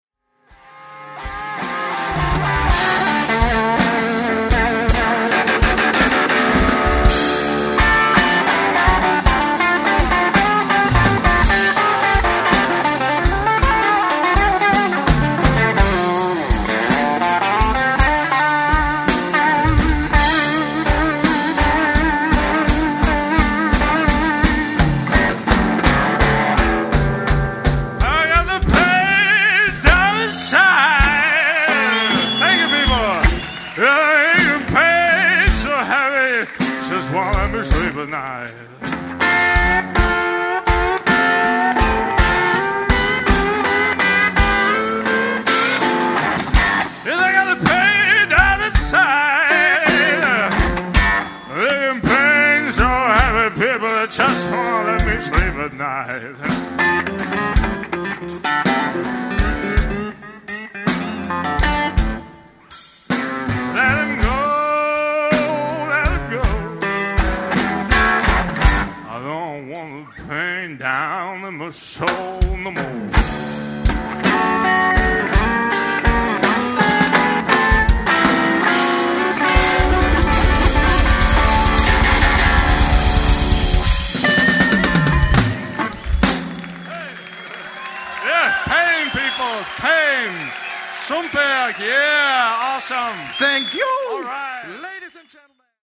Blues-Trio aus OÖ
voc/2xgit/drums) für maximal „bluesigen“ Hörgenuss.